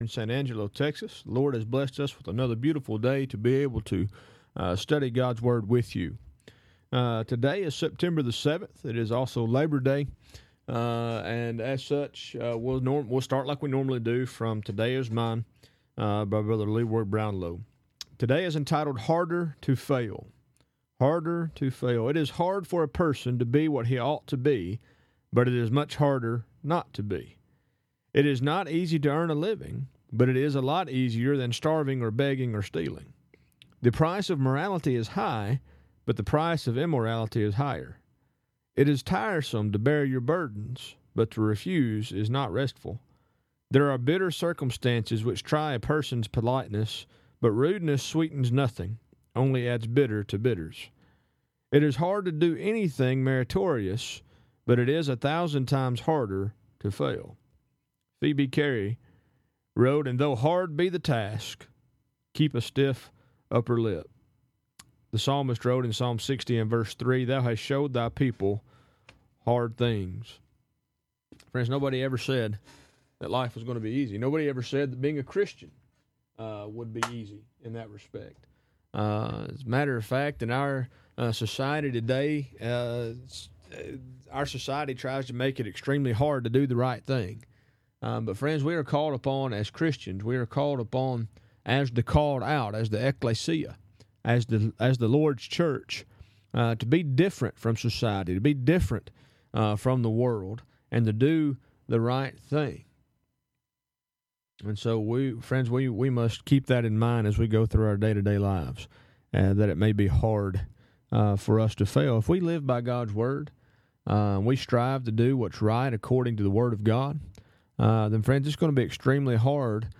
Program Info: Live program from the Nesbit church of Christ in Nesbit, MS.